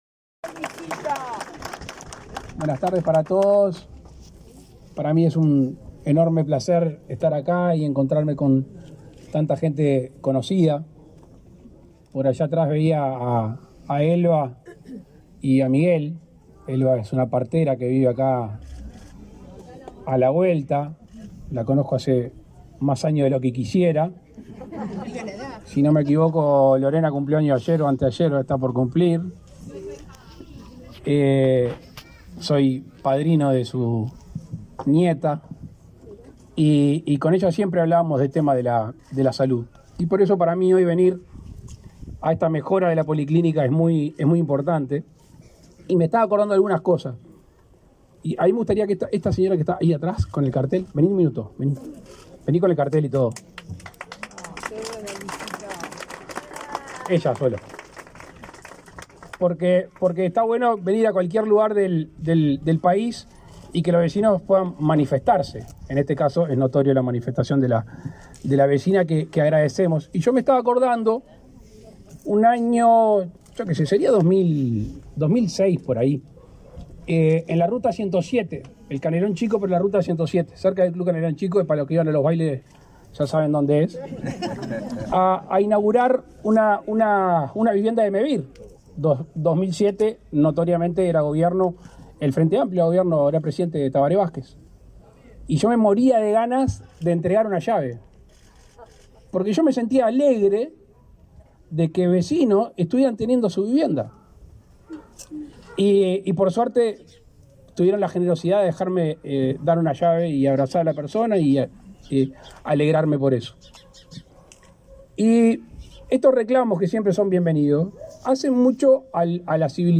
Palabras del presidente de la República, Luis Lacalle Pou, en policlínica de barrio Obelisco
En la oportunidad, el presidente Lacalle Pou realizó declaraciones en el evento.